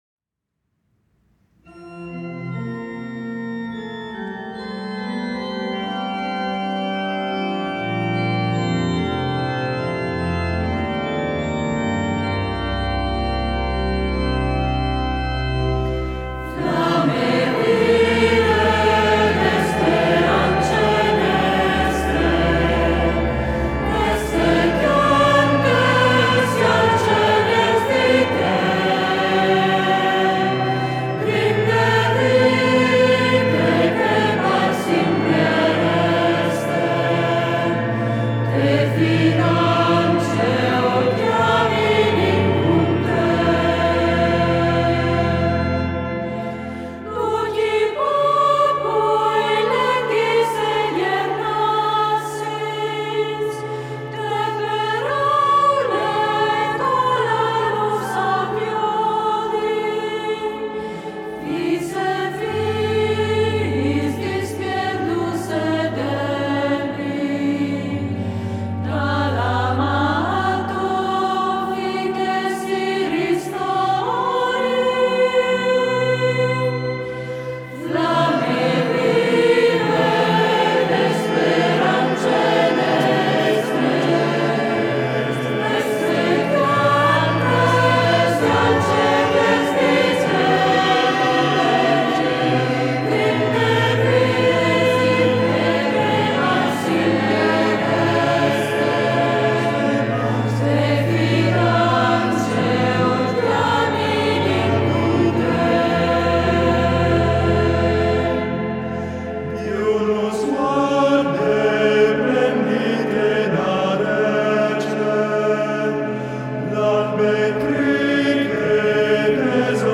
location recording